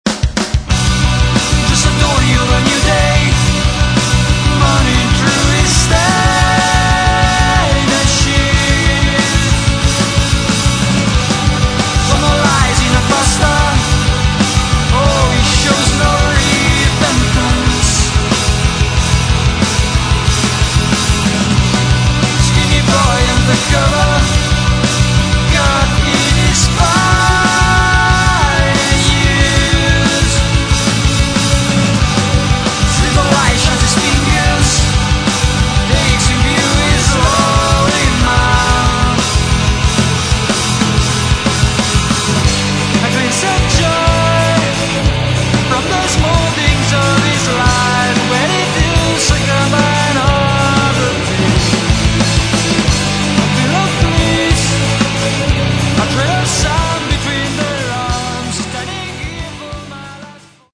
Metal
вокал, гитары
бас
ударные